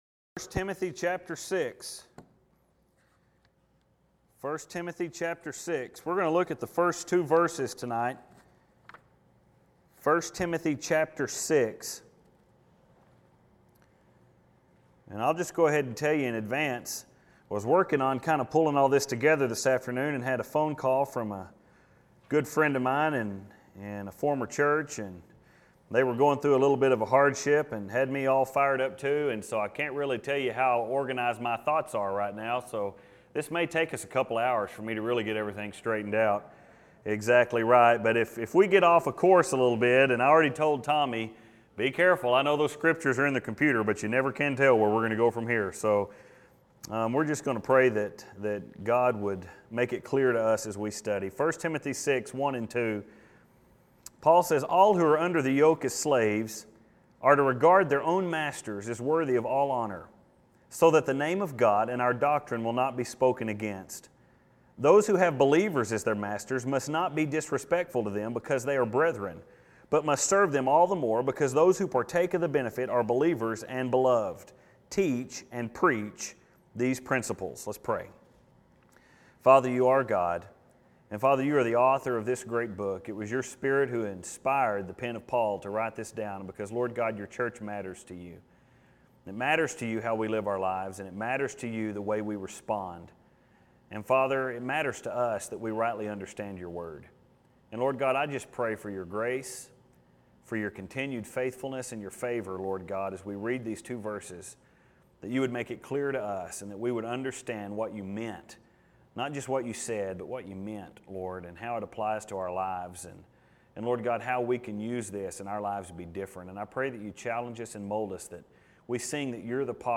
And I don’t know about you, but after so many sermons on pastor qualifications I am ready for something that convicts more people than just myself.